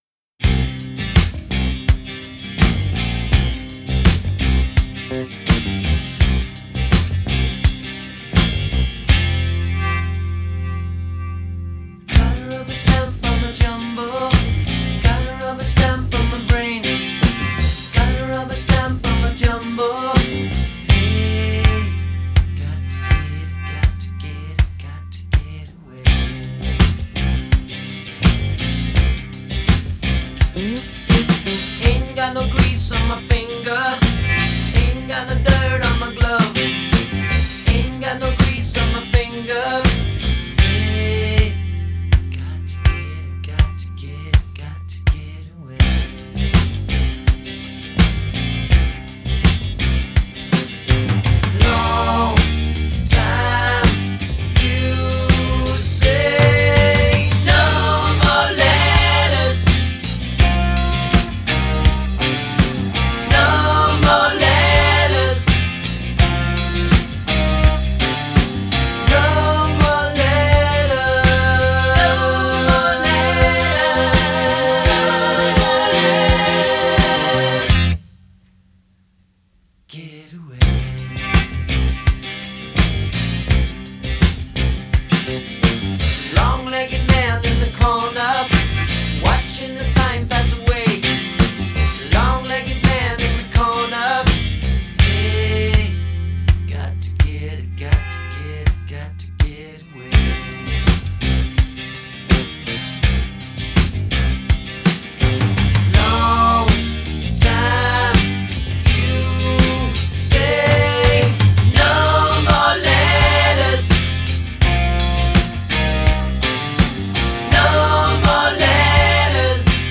Genre: Soft Rock